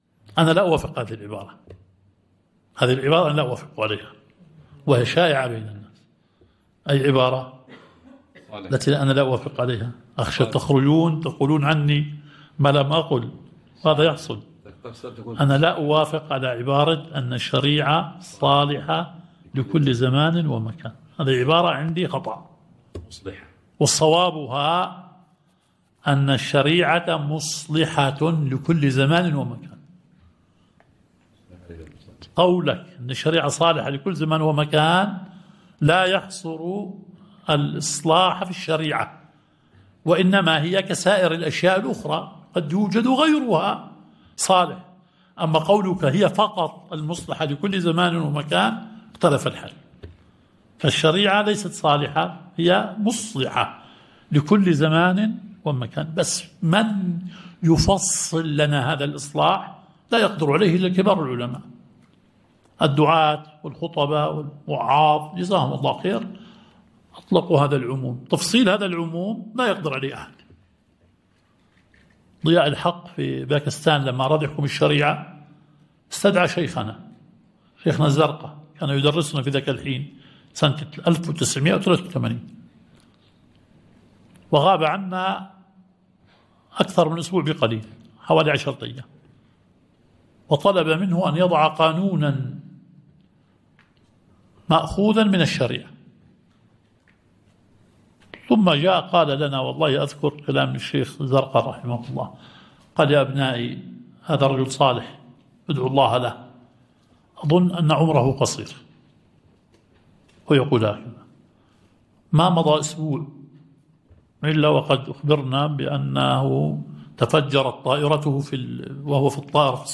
الدرس الثاني عشر – شرح مبحث العام والخاص في أصول الفقه